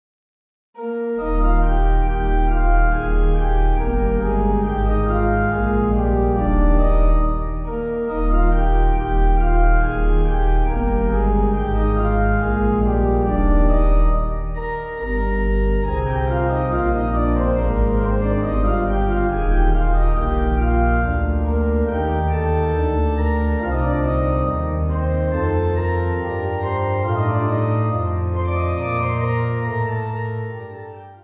Organ
Easy Listening   Eb